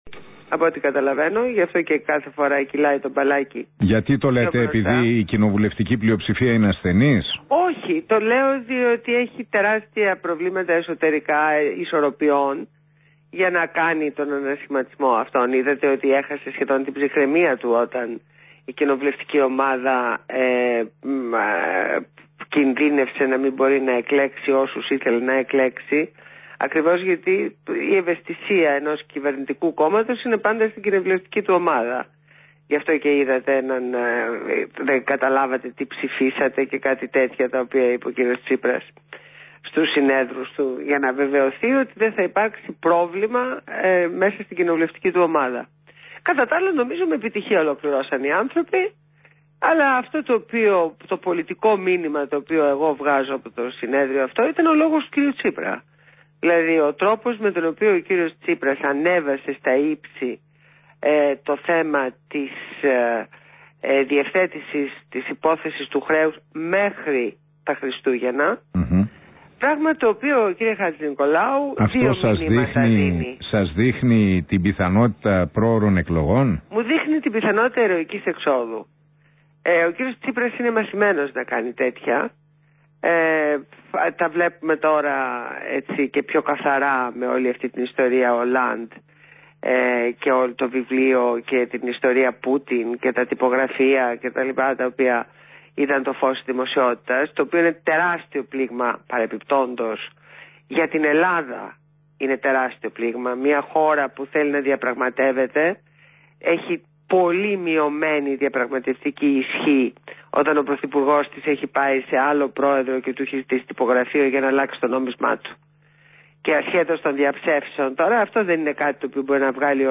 Συνέντευξη στο ραδιόφωνο του REALfm